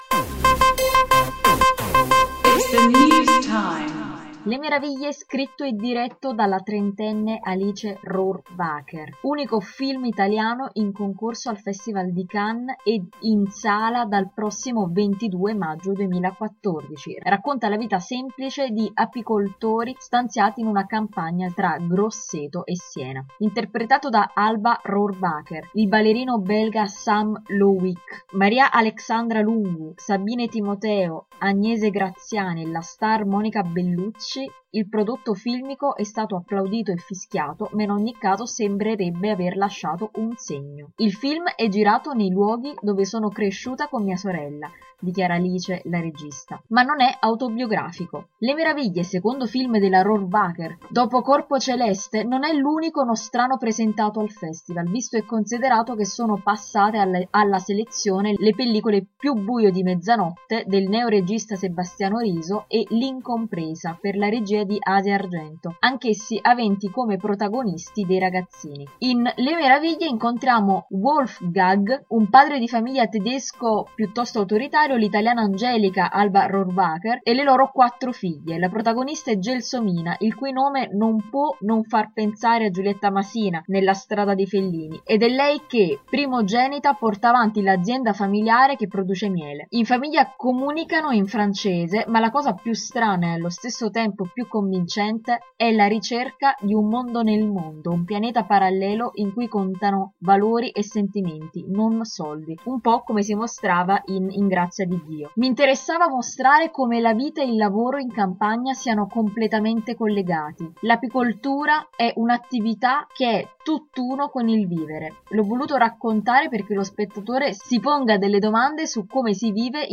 Se vuoi ascoltare l’articolo letto dalle nostre redattrici clicca qui